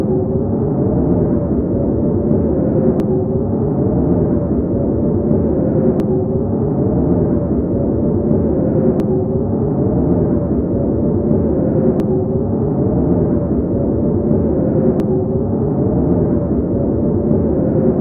grave.ogg